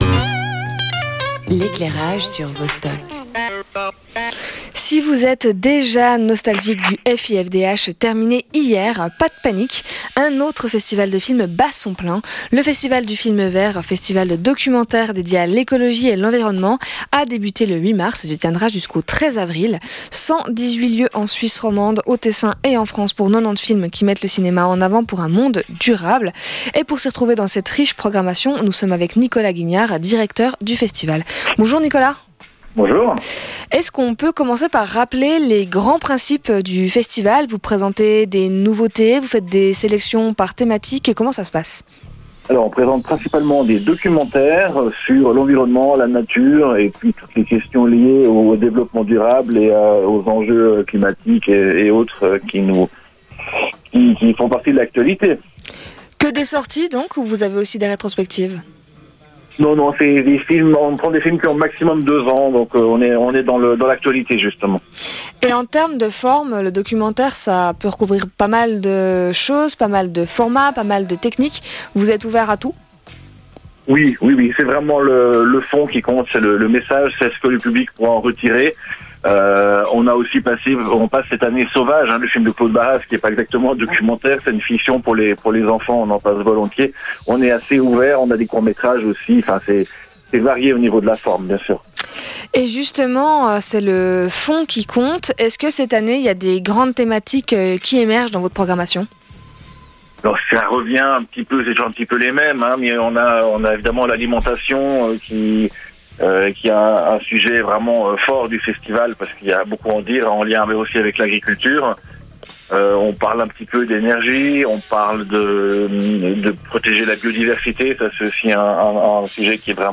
Invité
Animation